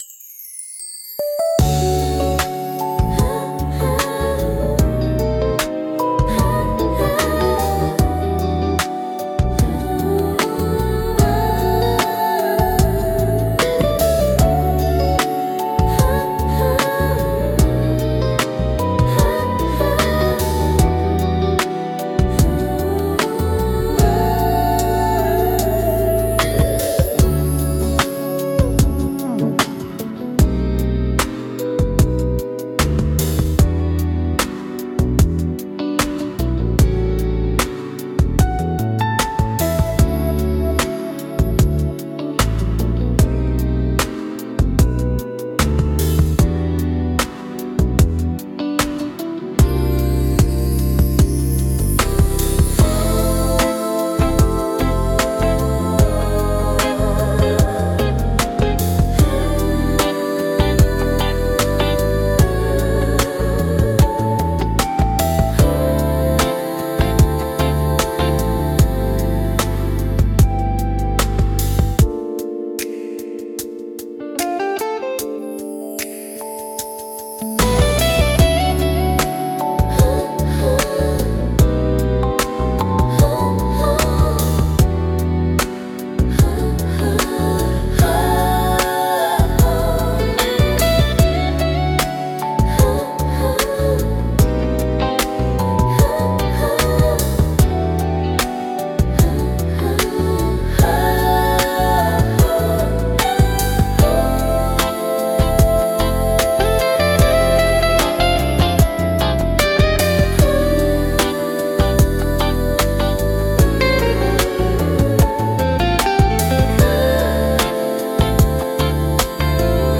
穏やかで心地よい雰囲気作りに重宝されるジャンルです。